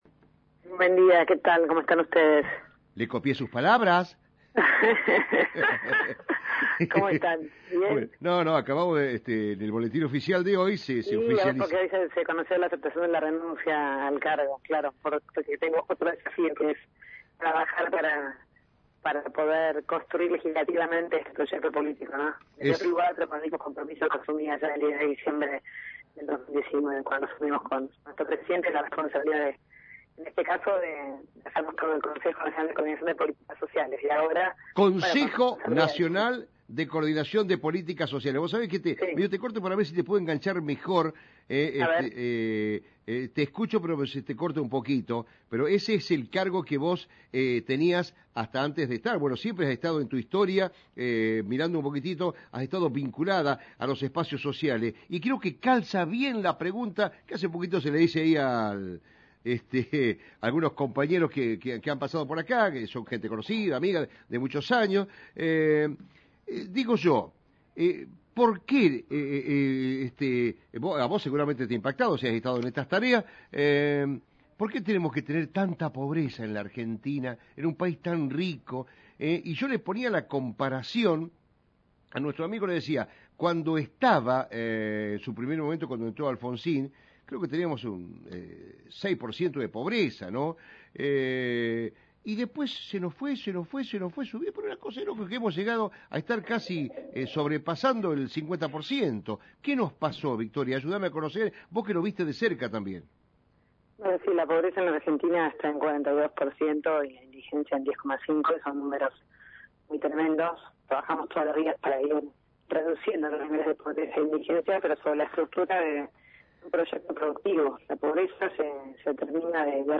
Tras su visita a Pergamino, Victoria Tolosa Paz dialogó con la radio – Radio Mon Pergamino